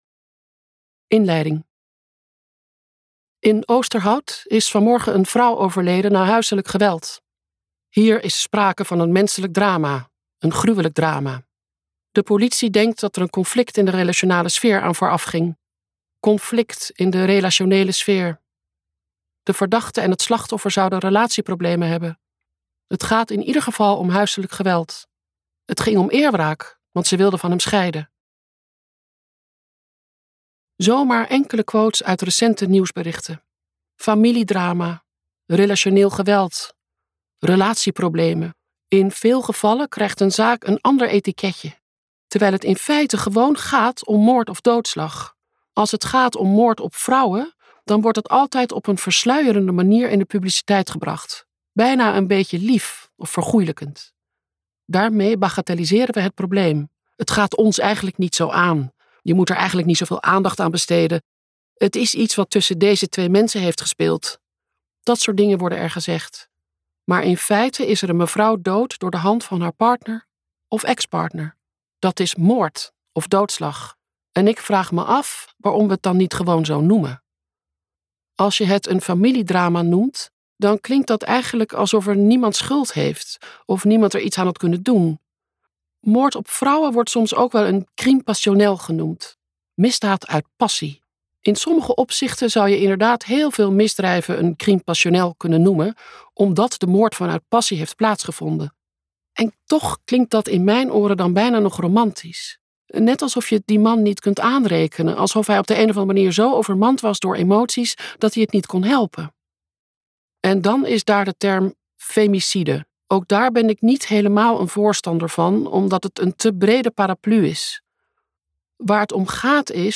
Ambo|Anthos uitgevers - Zij is van mij luisterboek